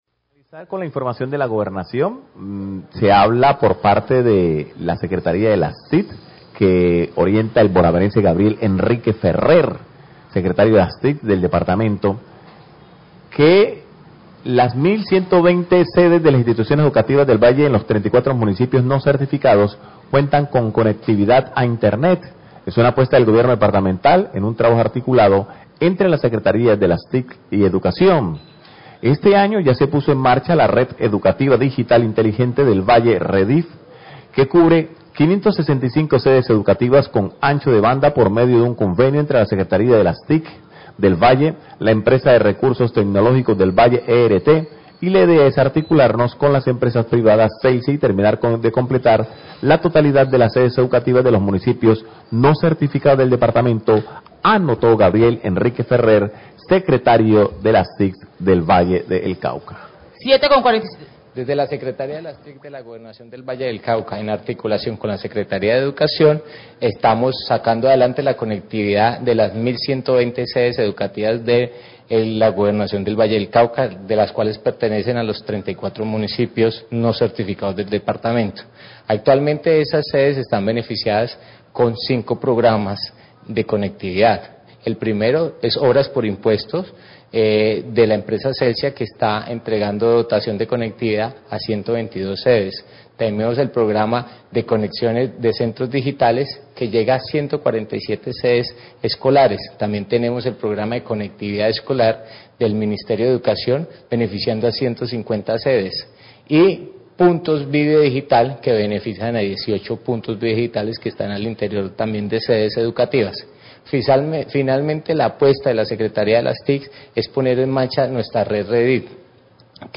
Radio
El Secretario de las TIC Valle, Gabriel Enrique Ferrer, habla de la conectividad a internet para 1120 escuelas públicas de los 32 municipios no certificados, a través de una alianza con Celsia, la ERT y la Gobernación del Valle.